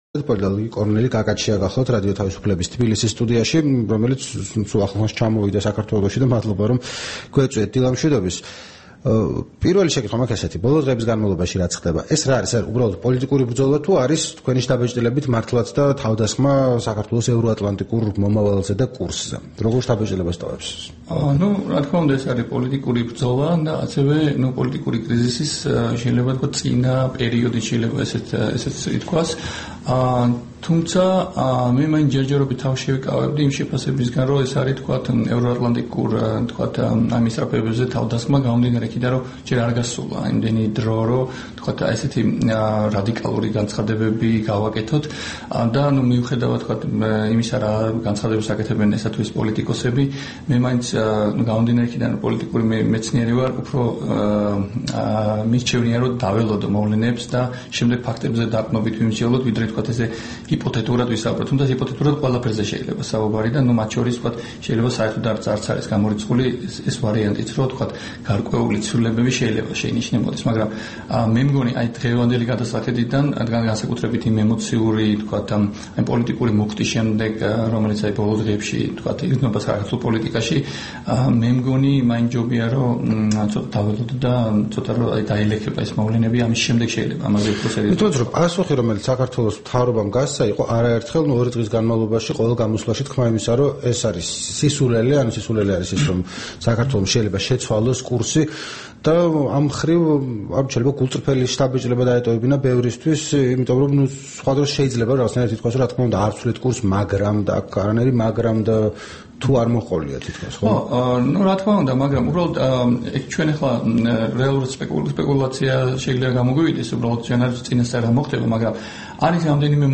რადიო თავისუფლების თბილისის სტუდიაში
საუბარი